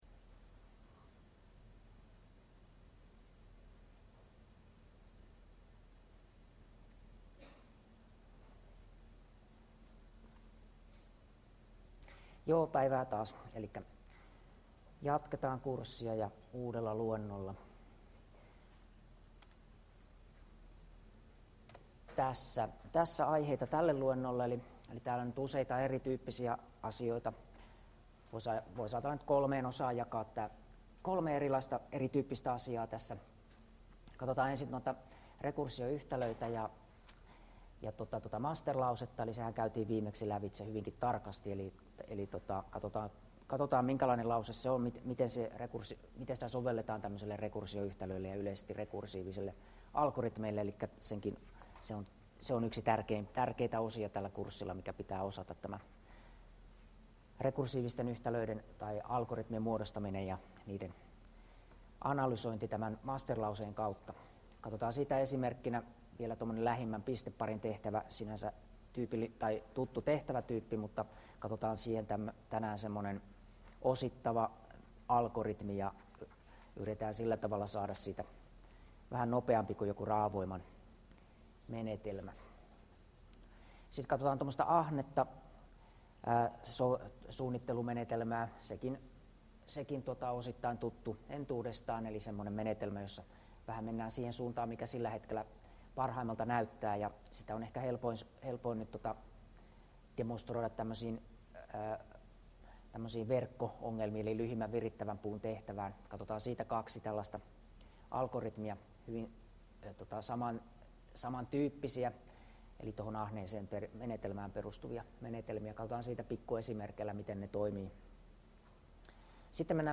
Luento 9 — Moniviestin